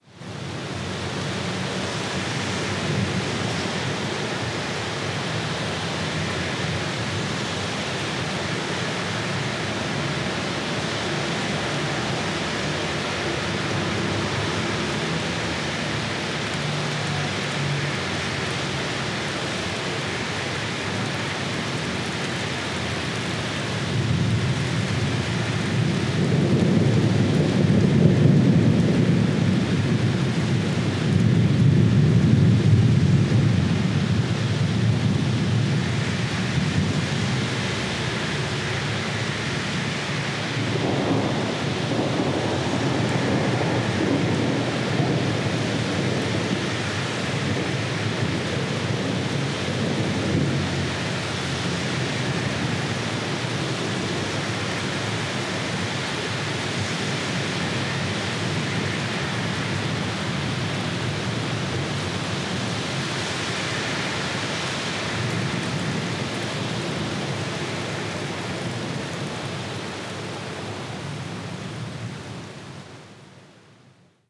Ambiente de tormenta fuerte
trueno tronar tormenta diluviar electricidad llover lluvia
Sonidos: Agua